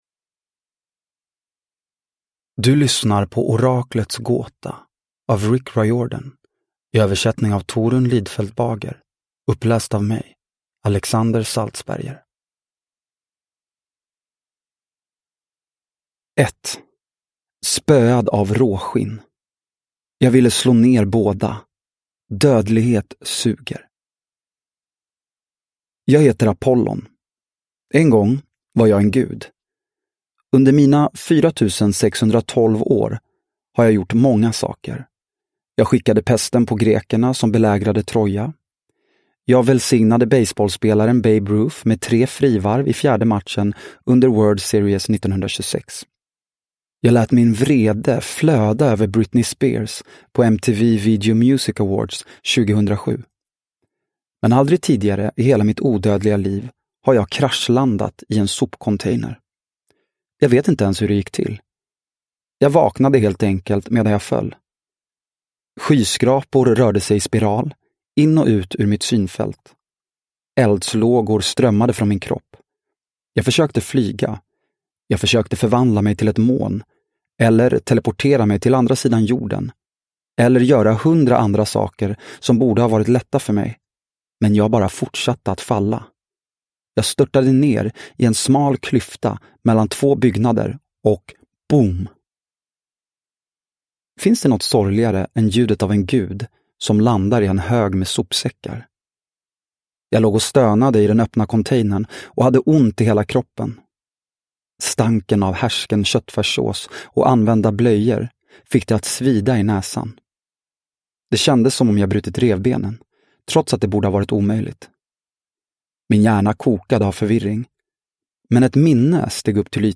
Oraklets gåta – Ljudbok – Laddas ner